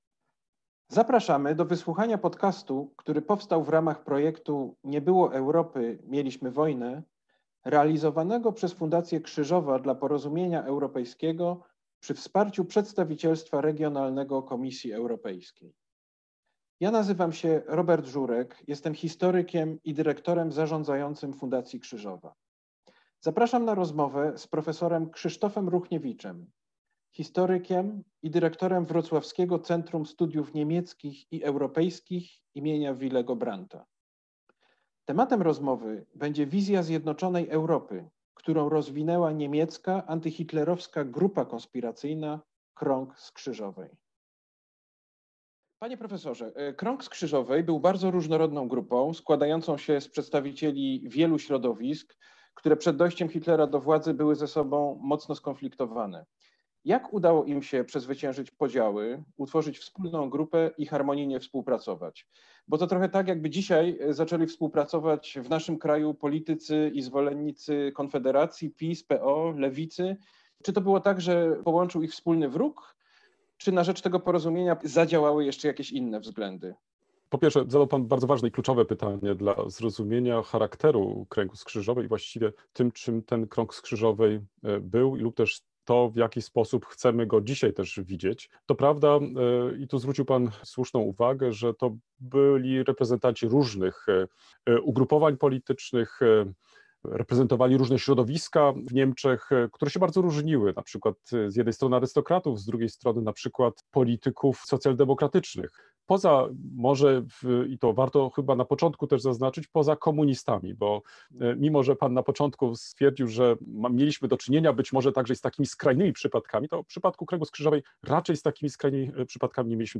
Rozmowa z ekspertem